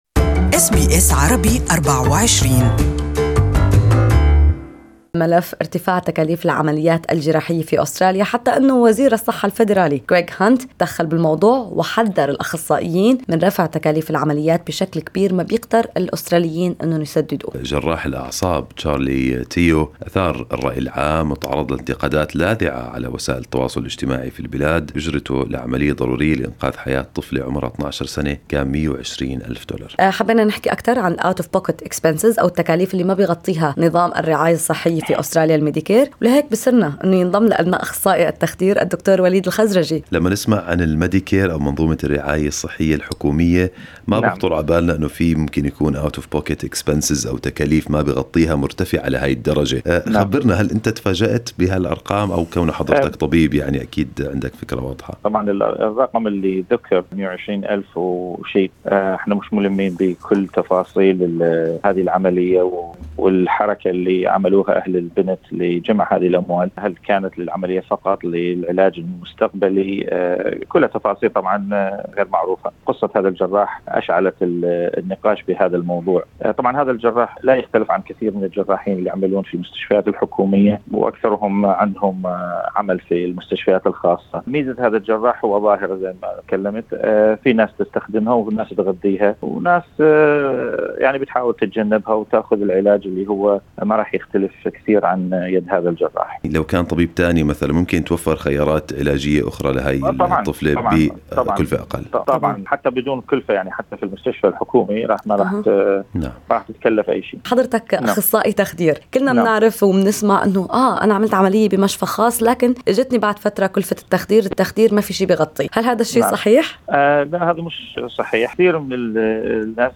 ويستثنى من هذه القاعدة العمليات التجميلية الاختيارية كشد الوجه، ولكن تبقى عمليات إنقاص الوزن مشمولة في المديكير وفق شروط معينة. استمعوا إلى المقابلة